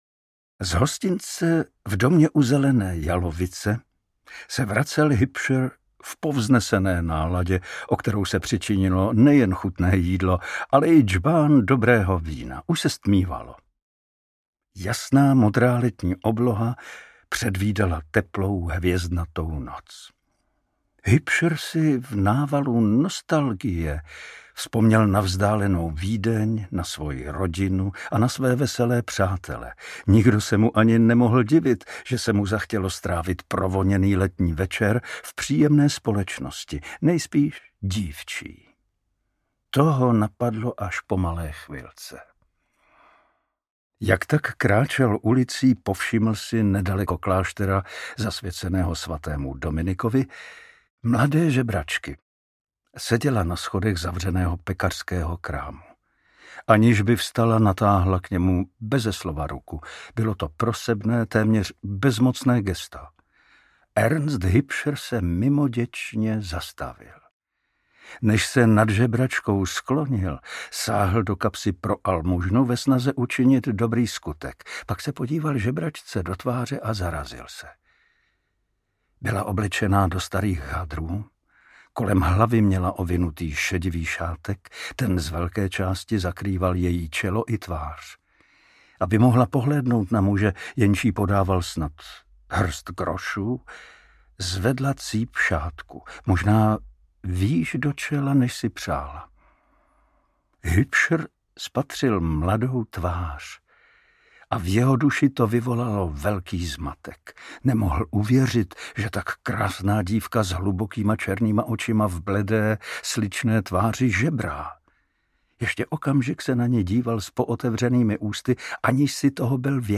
Katova vinice audiokniha
Ukázka z knihy
Čte Pavel Soukup.
Vyrobilo studio Soundguru.